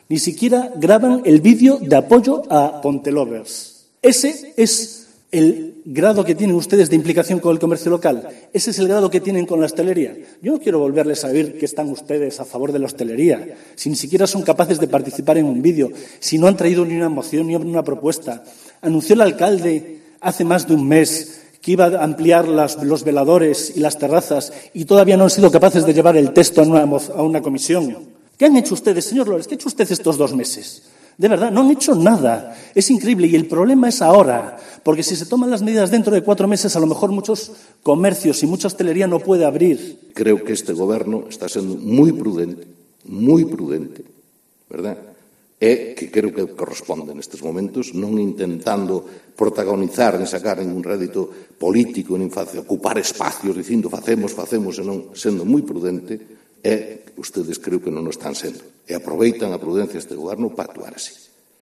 Fragmento de las intervenciones de PP y BNG en el pleno de Pontevedra